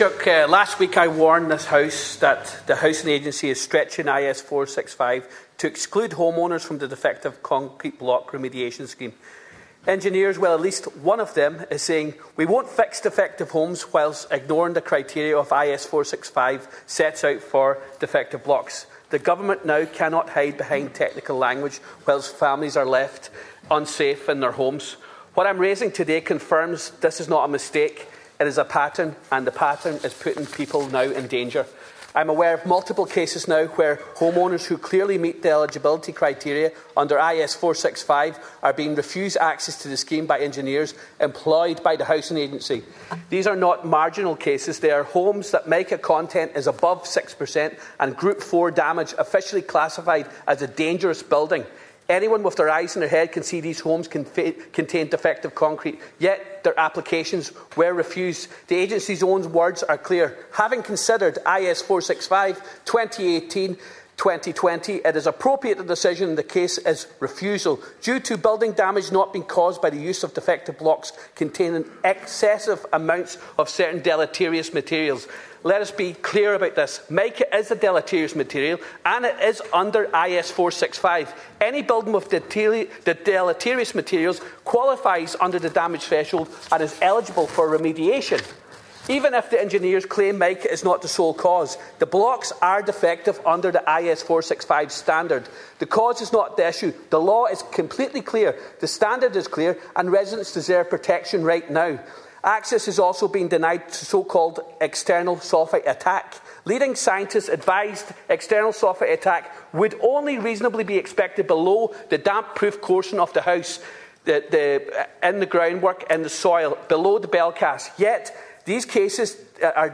Deputy Ward told Taoiseach Michael Martin on the floor of the Dail that homeowners who clearly qualify under I.S. 465 are being deliberately shut out, with technical interpretations used as a shield while people continue to live in buildings officially classified as dangerous.
You can listen to the full exchange here –